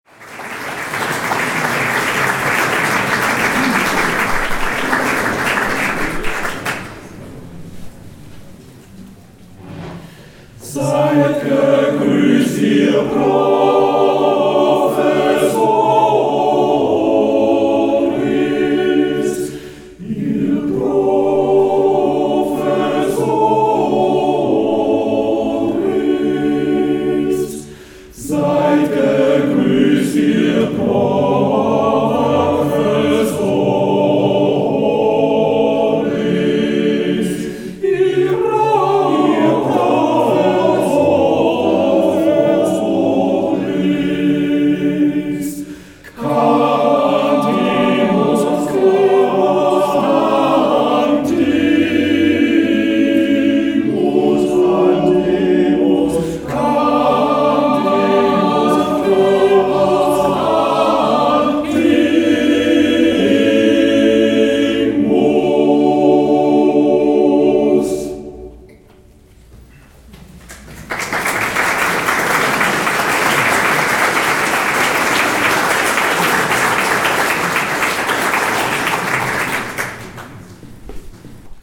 Hörbeispiele von unserer CD vom 27. Juni 2007: 70. Hausmusik.